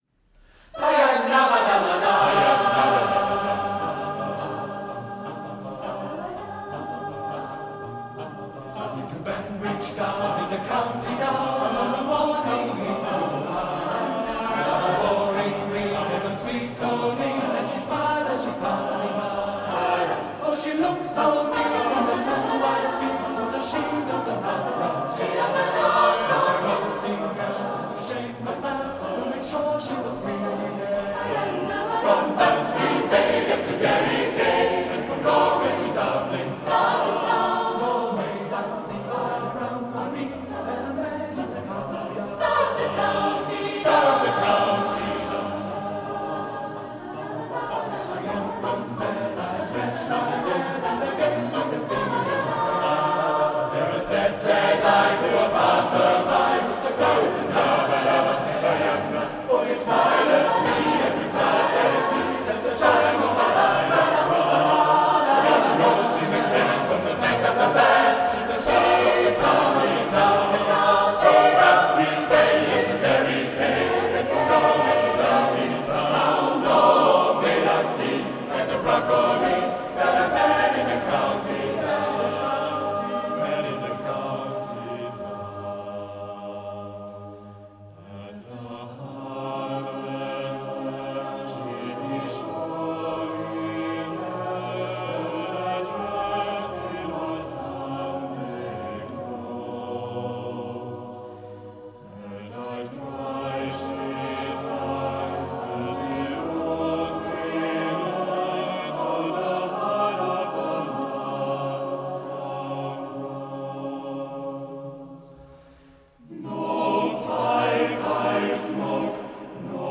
CD 5 - Live in Concert 2018